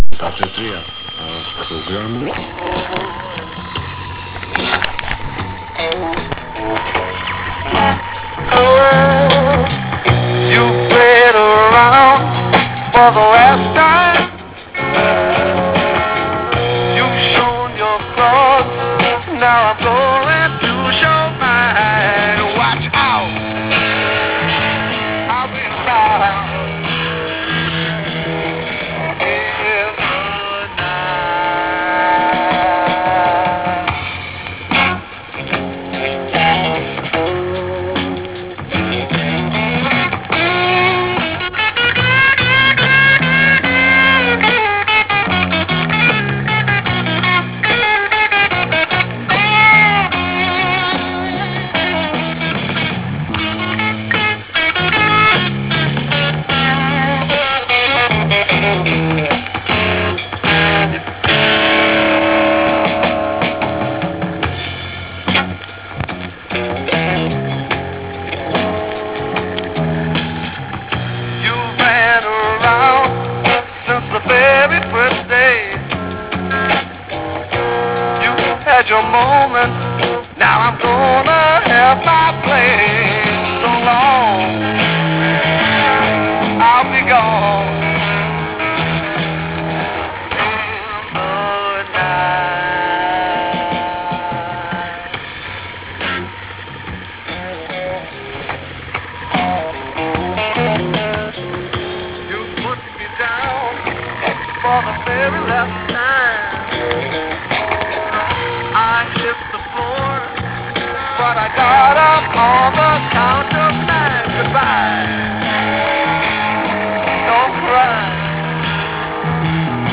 Прилагам мостра (ужасна, свалена от "магнет" ):